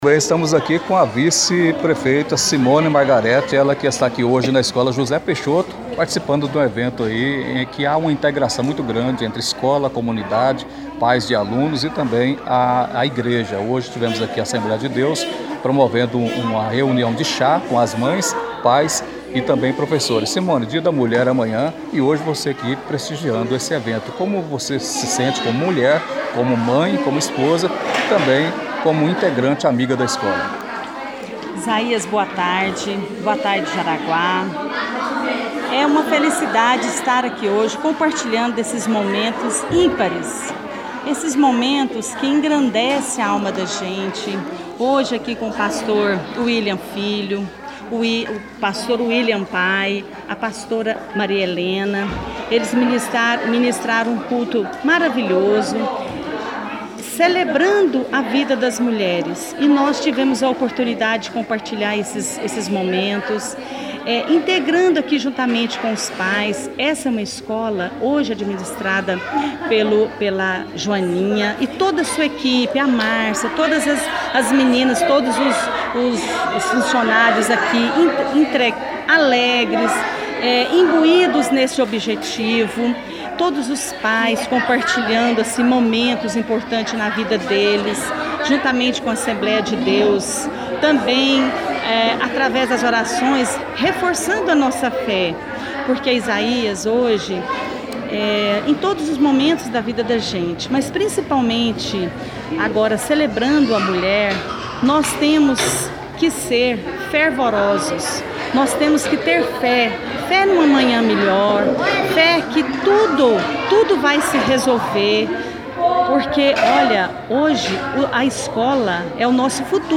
entrevista-simone.mp3